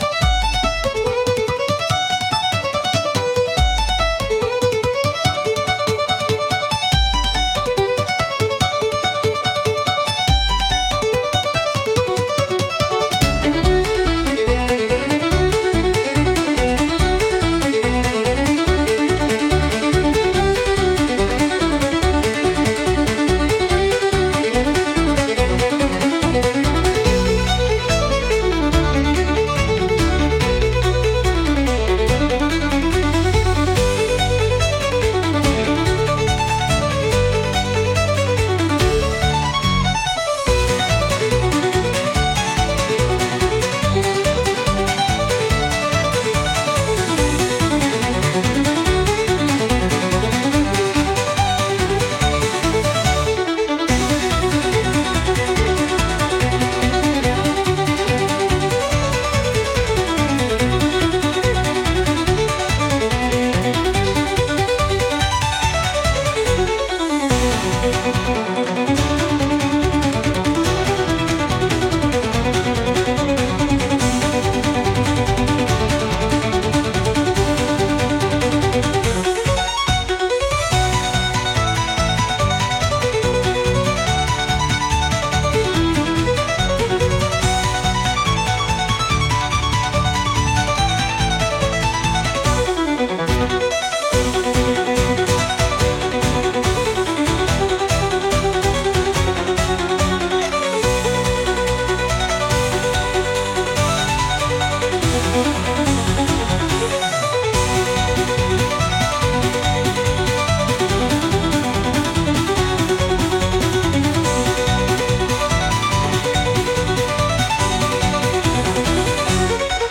Instrumental / 歌なし
タイトル通り、生命力あふれるテンポの速いケルト音楽。
勢いを落とすことなく最後まで駆け抜ける構成は、技術力の高さとスピード感をアピールするのに最適。